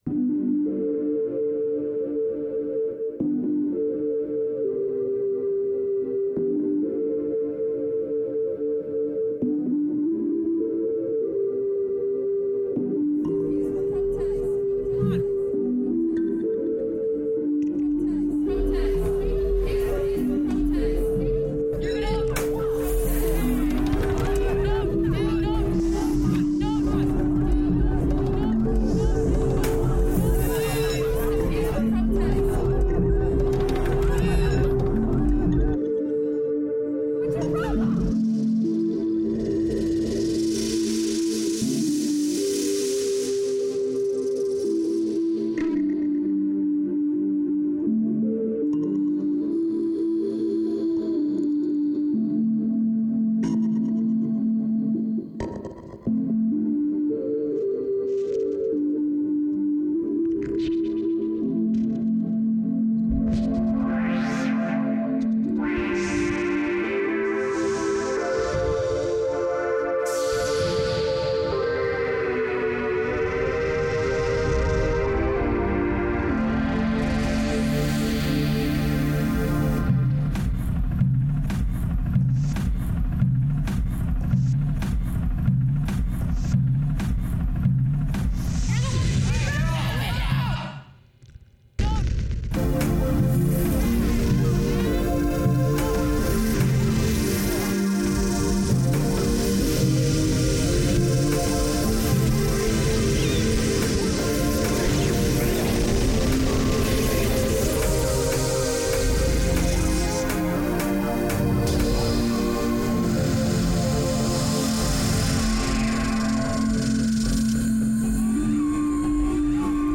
It was a peaceful demonstration when suddenly someone broke a window. I liked to sound of breaking glass and also the reactions.
Structure and chaos, different layers of sound finding together, fighting for the same but also against each other however having this peaceful base. I experimented with the different sounds, cut the original field recording into tiny pieces – words, rhythms, noise and put it back together.